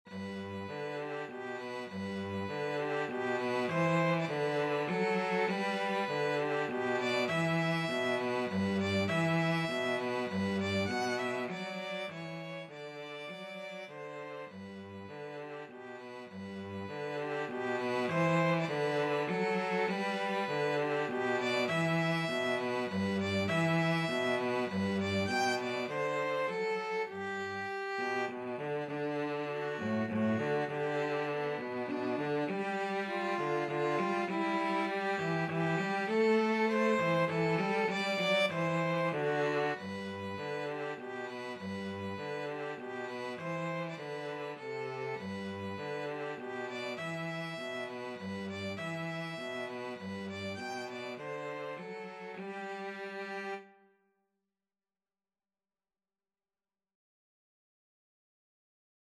3/4 (View more 3/4 Music)
Moderato
Classical (View more Classical Violin-Cello Duet Music)